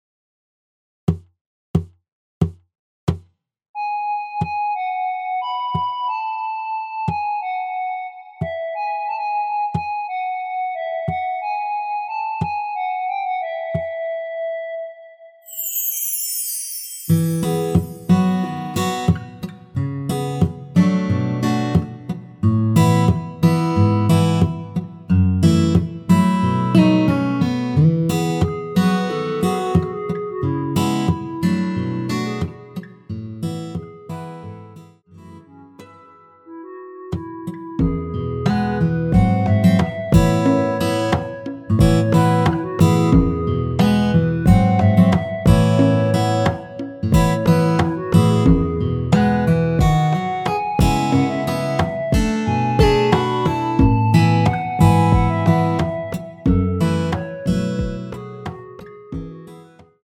노래 들어가기 쉽게 전주 1마디 넣었으며
노래 시작 앞부분이 무반주라서 기타 바디 어택으로
원키 멜로디 포함된 MR입니다.
앞부분30초, 뒷부분30초씩 편집해서 올려 드리고 있습니다.